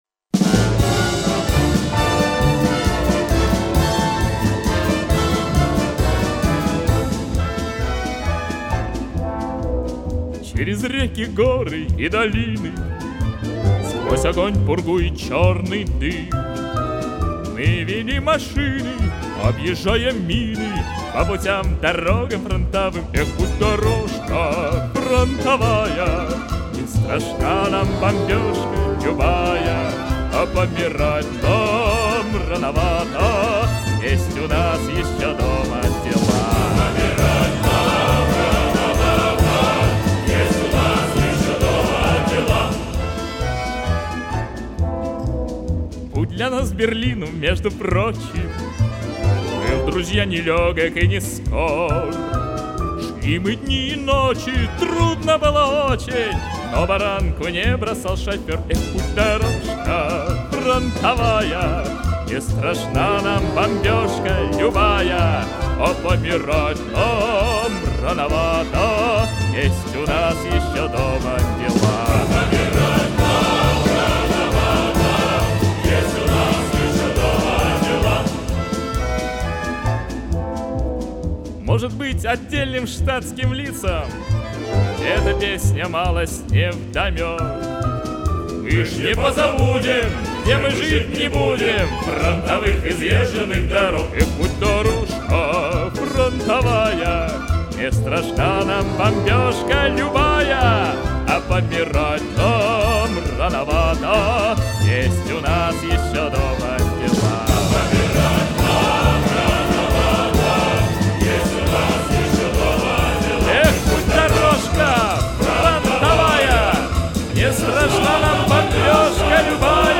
современная запись.